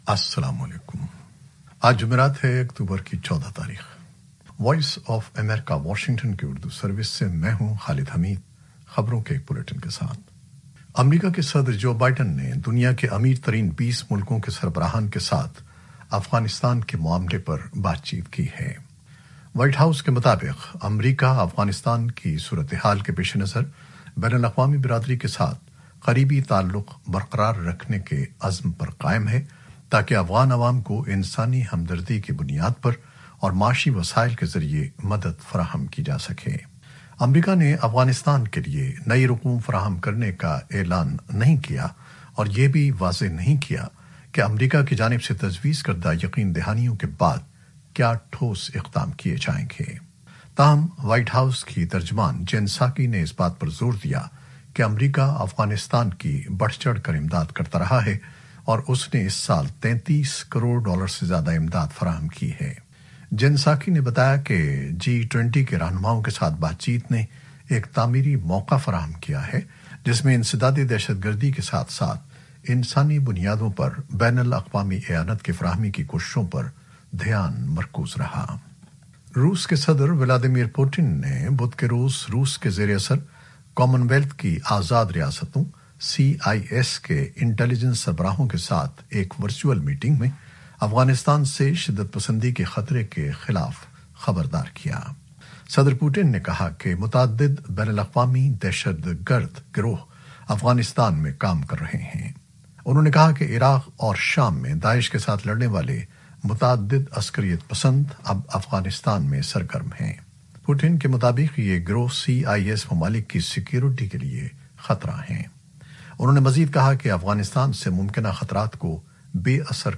نیوز بلیٹن 2021-14-10